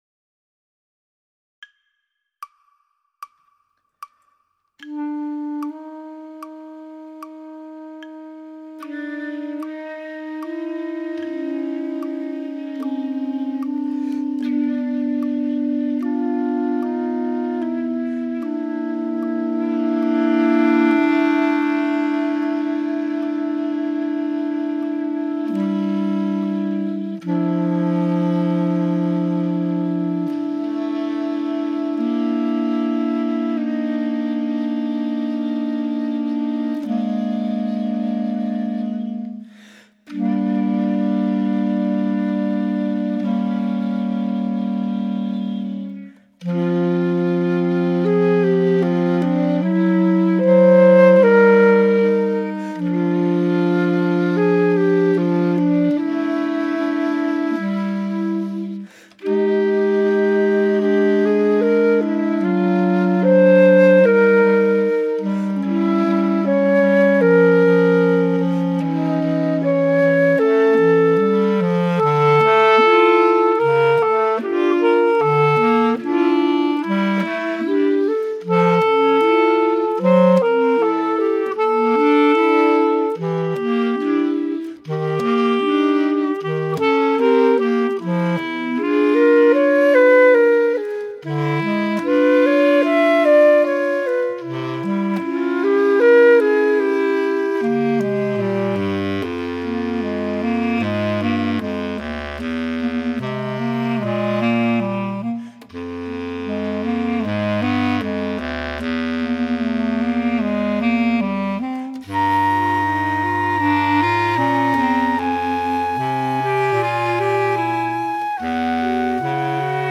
Playalong Music Minus One: [audio
ohne Tenorsax